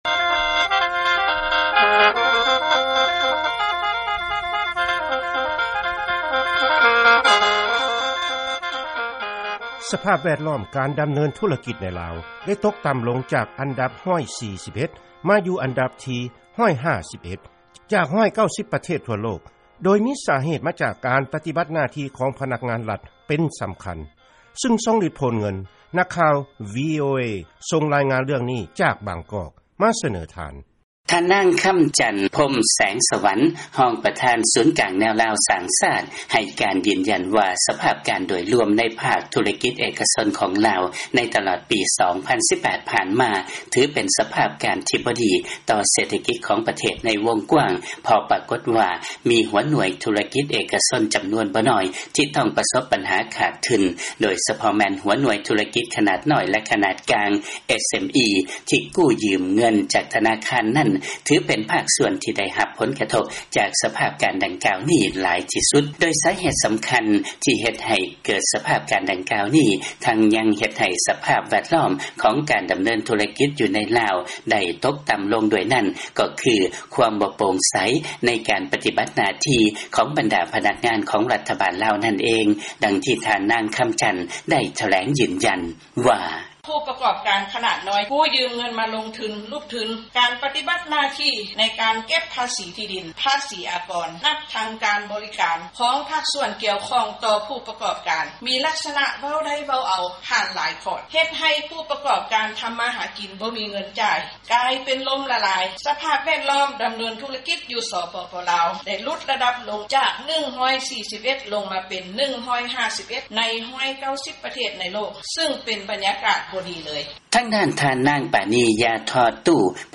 ເຊີນຟັງລາຍງານ ດັດຊະນີຄວາມສະດວກ ໃນການເຮັດທຸລະກິດ ໃນລາວ ທີ່ຕົກຕໍ່າລົງ